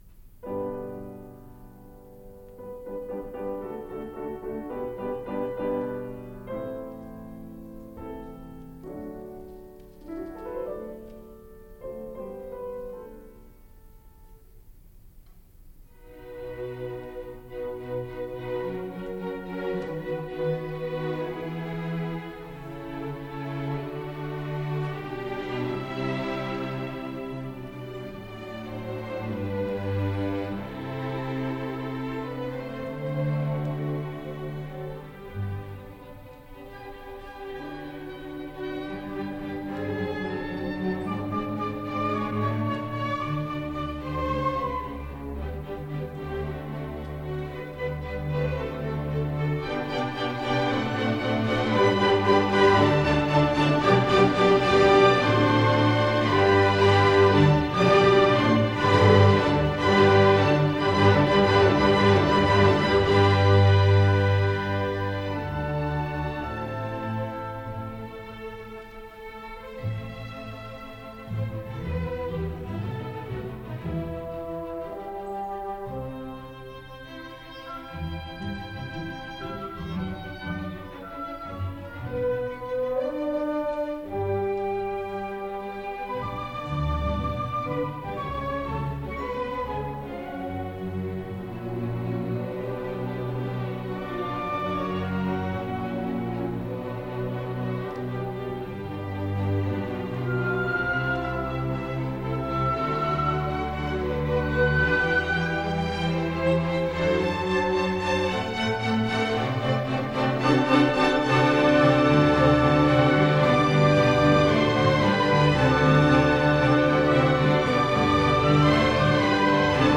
Classical
Allegro moderato Orchestra